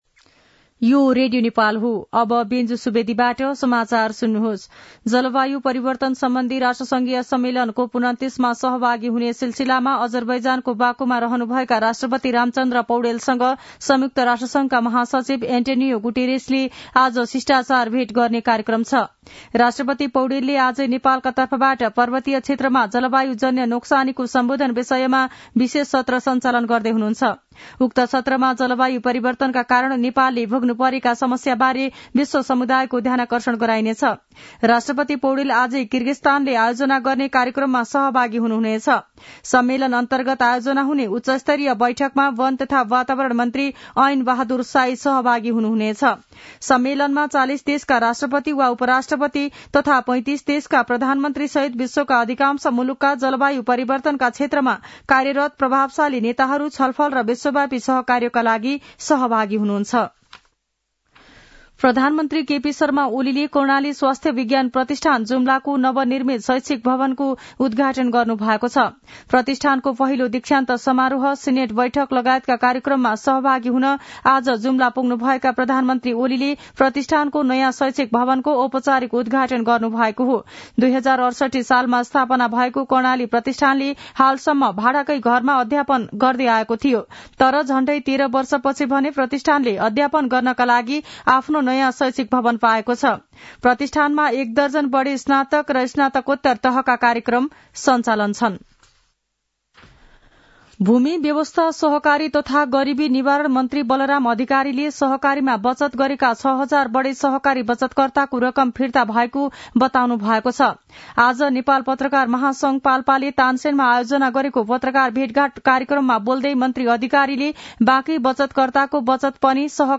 दिउँसो १ बजेको नेपाली समाचार : २९ कार्तिक , २०८१
1-pm-nepali-news-.mp3